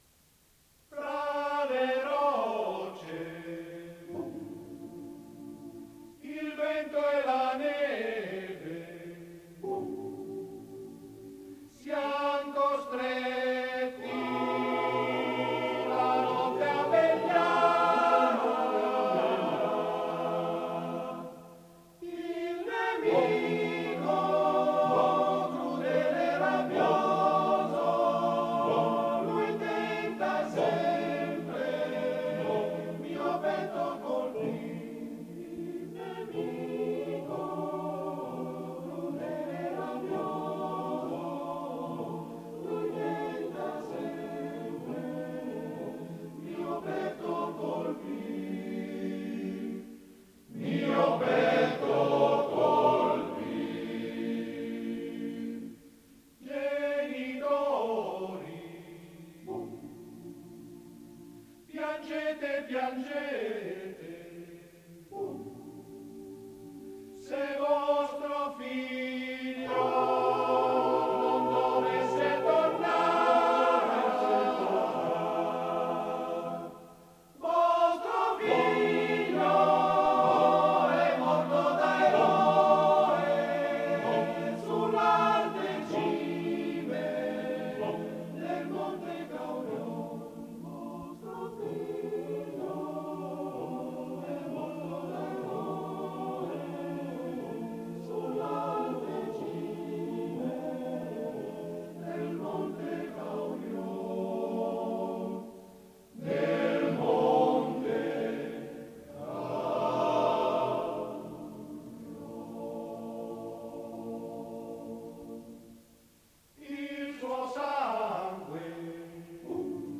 Patrimoni musicali della cultura alpina
Esecutore: Coro Monte Cauriol